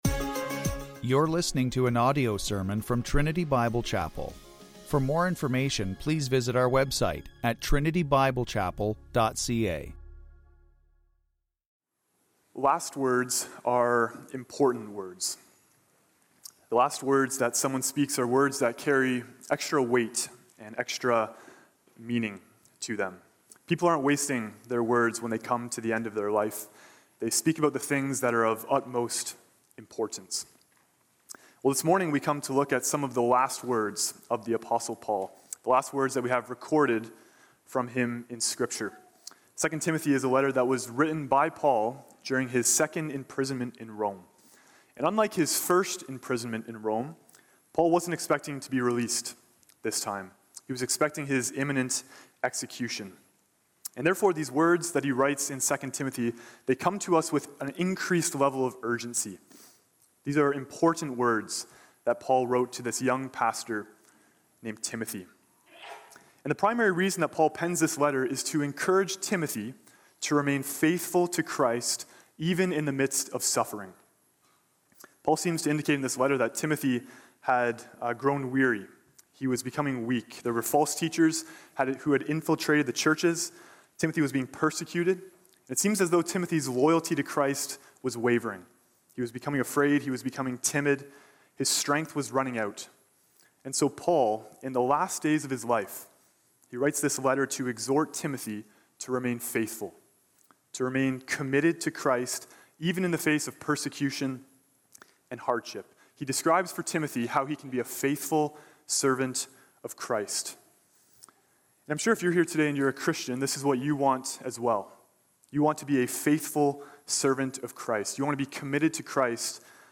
2025 Scripture: 2 Timothy 2:1-10 Series: Single Sermons